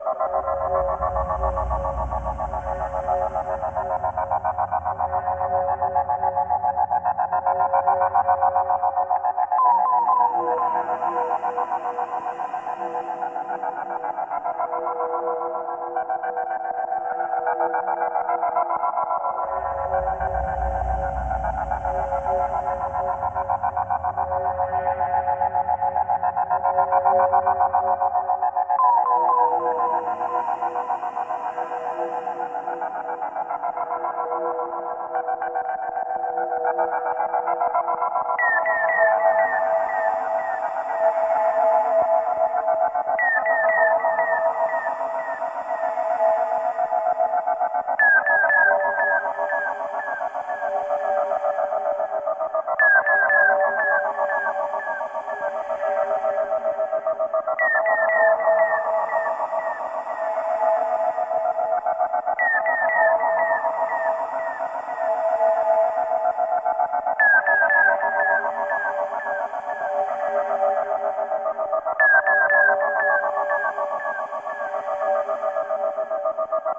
A cool sound-design kind of track.
LoFi Version: